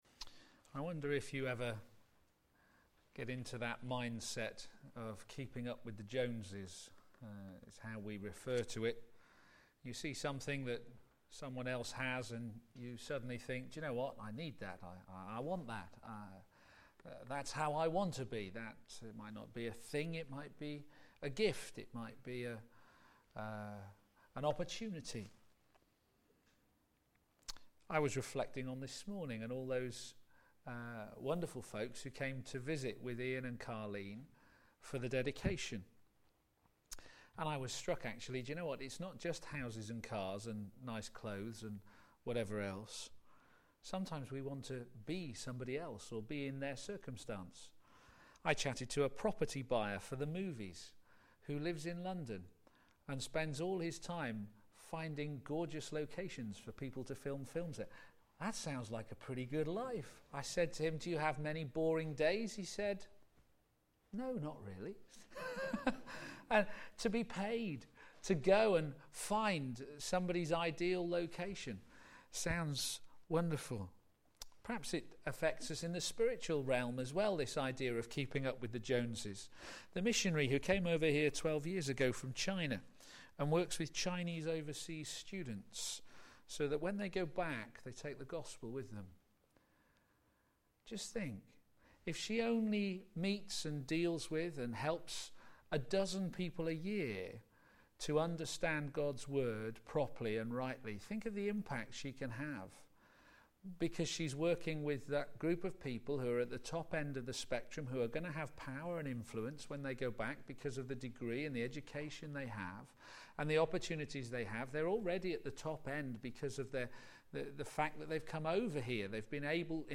Built to last Sermon